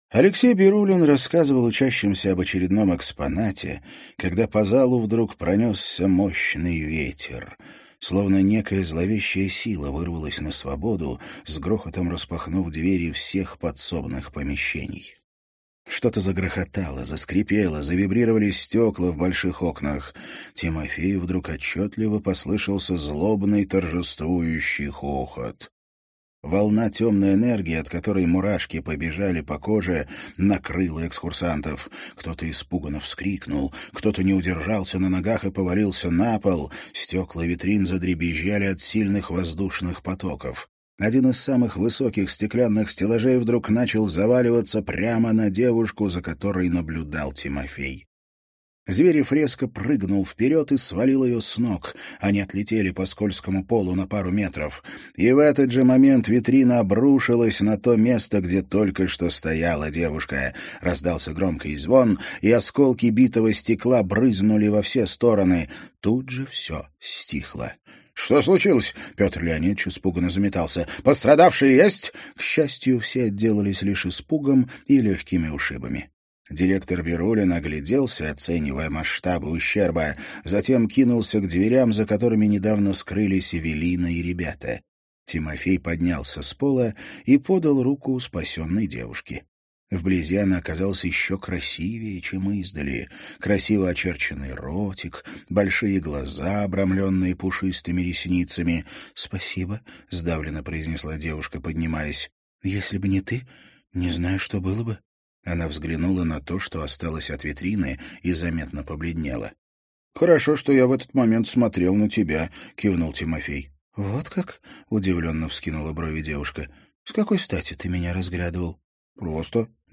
Аудиокнига Пандемониум. Верховная Мать Змей | Библиотека аудиокниг